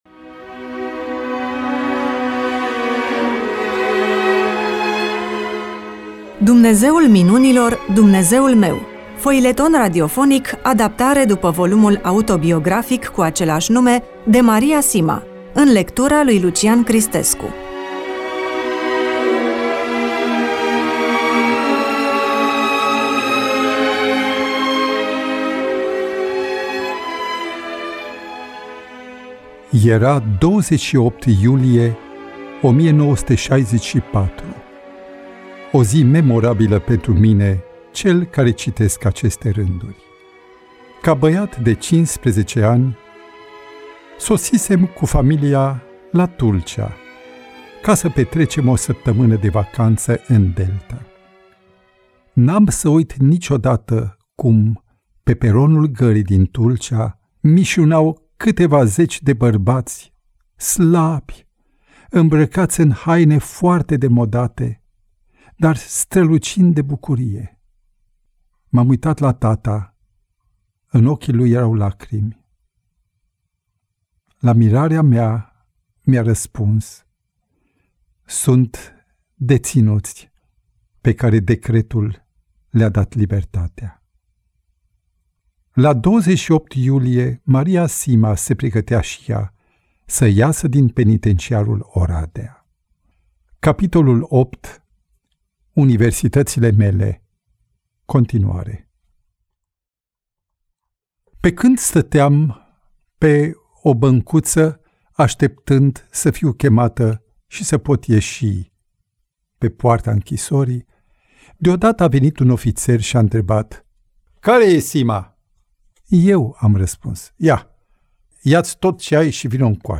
EMISIUNEA: Roman foileton DATA INREGISTRARII: 13.03.2026 VIZUALIZARI: 44